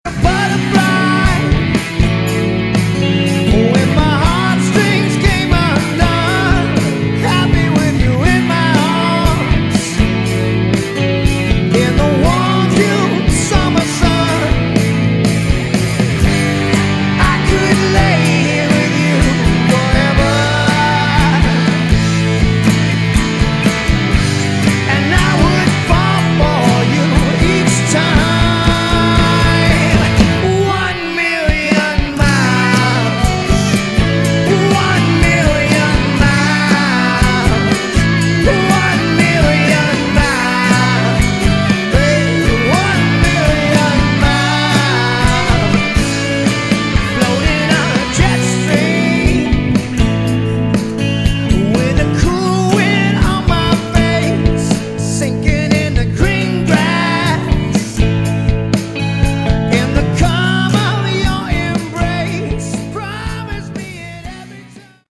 Category: Hard Rock
Lead Vocals, Acoustic Guitar
Backing Vocals
Guitar
Bass
Drums